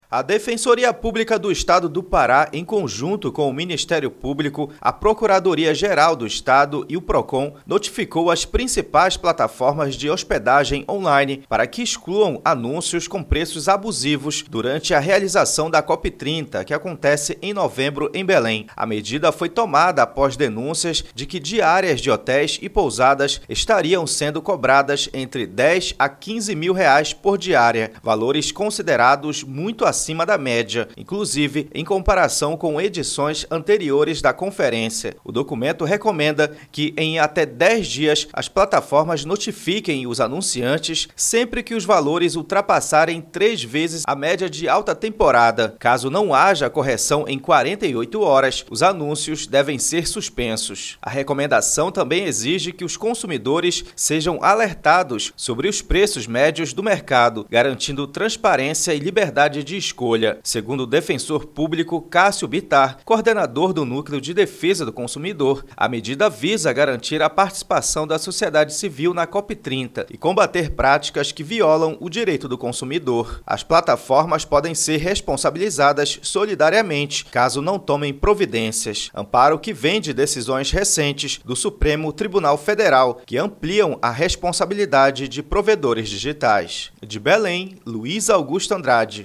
0---BOLETIM-DECISO-HOSPEDAGENS-COP.mp3